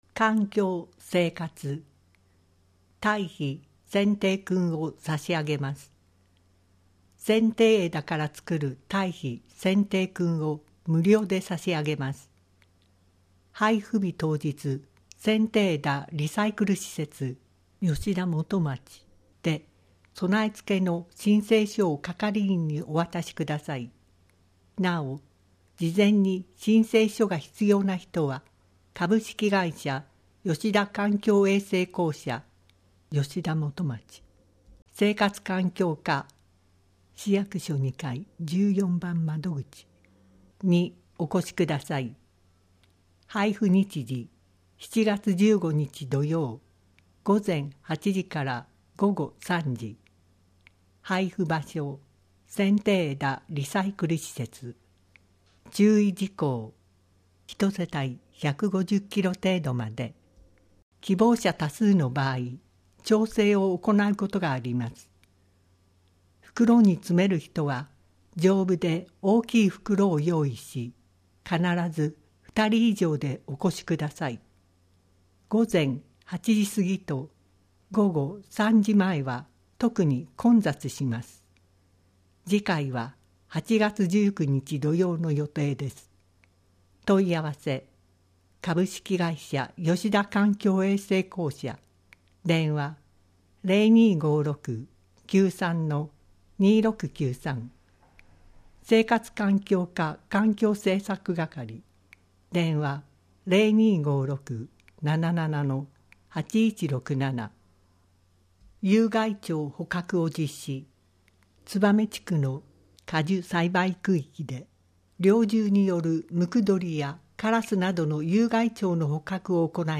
声の広報は、広報つばめを音読・録音したもので、デイジー版とMP3版があります。